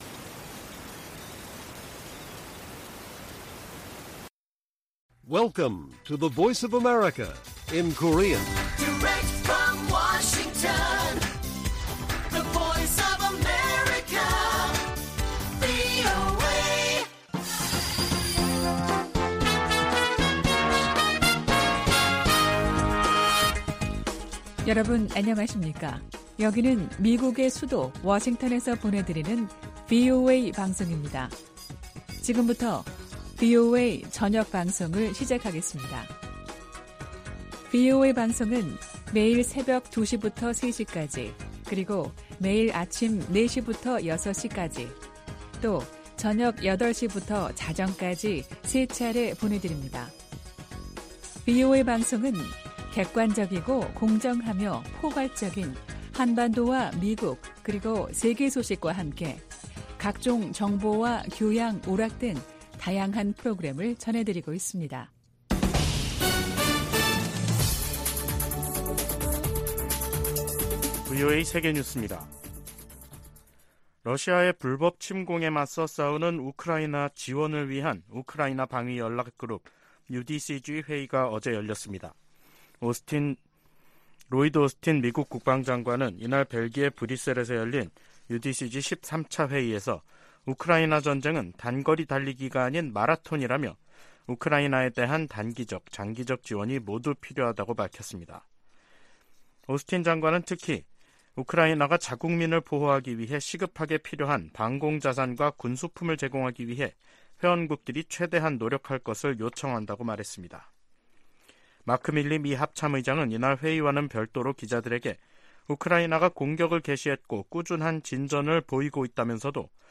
VOA 한국어 간판 뉴스 프로그램 '뉴스 투데이', 2023년 6월 16일 1부 방송입니다. 한국 합동참모본부는 북한이 발사한 단거리 탄도미사일 2발을 포착했다고 밝혔습니다. 미국은 핵추진 잠수함을 한국에 전개하면서 경고 메시지를 보냈습니다. 미한일 3국의 안보 수장들도 북한의 탄도미사일 발사에 대해 국제 평화와 안보를 위협한다며 규탄했습니다.